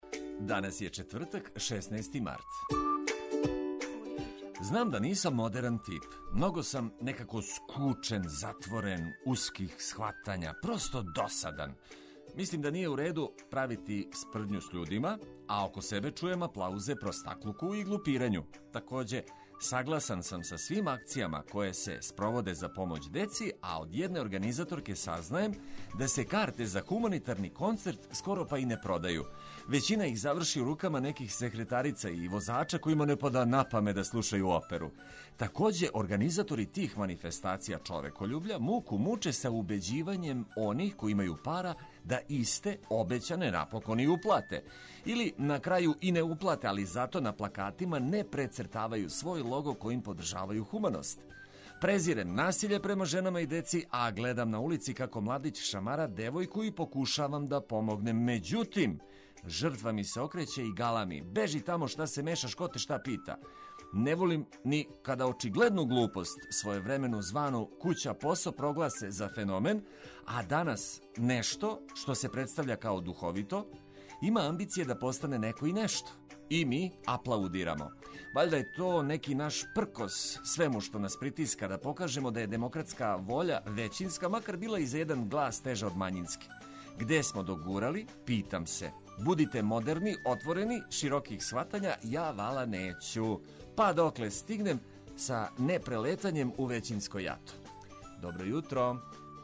Током јутра слушаћете сјајну музику коју ћемо прошарати кратким али корисним информацијама.